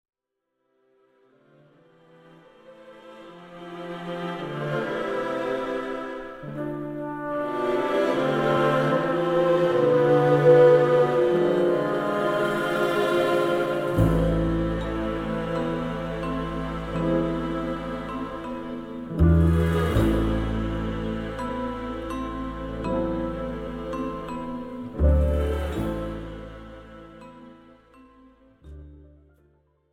instrumental backing track cover
• Without Backing Vocals
• No Fade